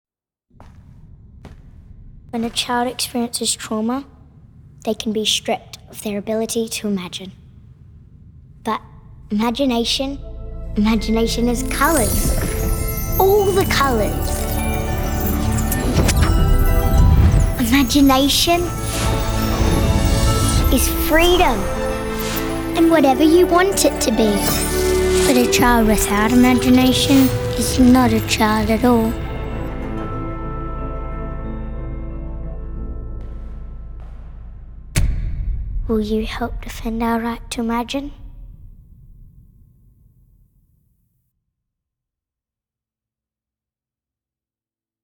This public service announcement was a pro-bono project meant to raise awareness and donation for at risk children. The Sound design is meant to work with the music to invoke a feeling of magic and wonder, which evolves into a bleak and stark reality.